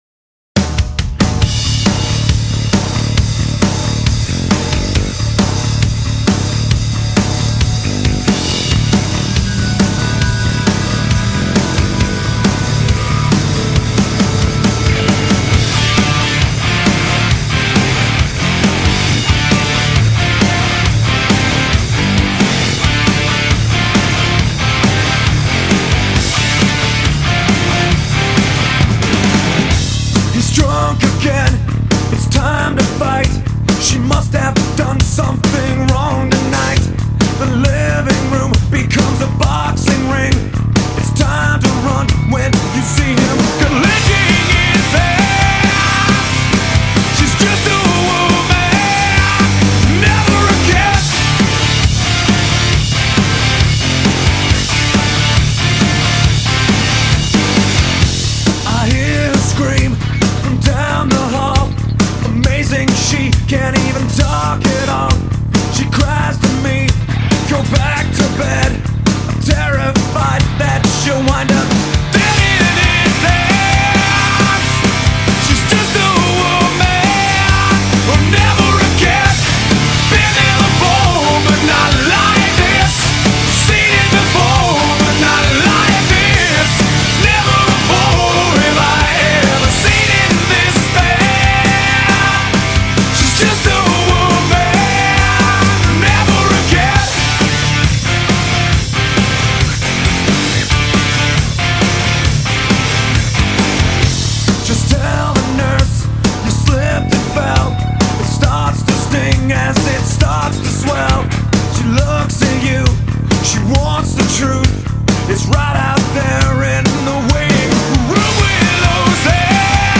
Genre: Rock.